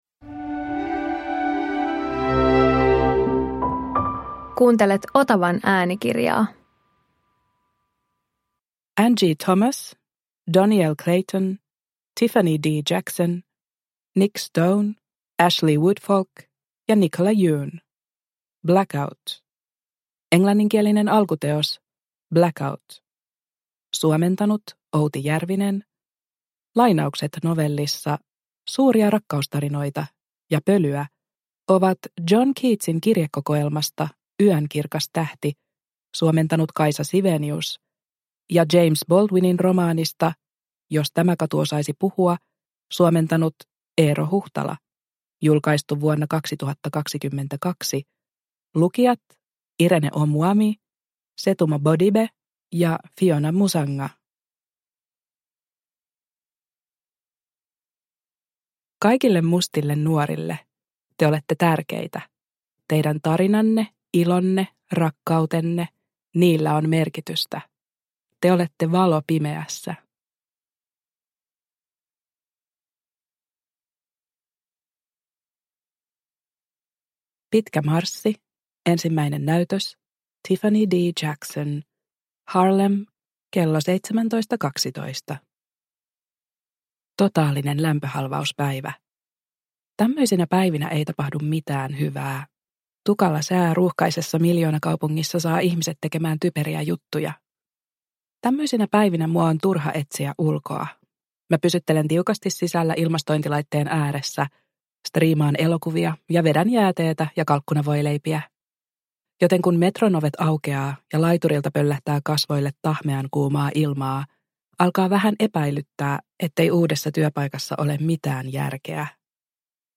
Blackout – Ljudbok – Laddas ner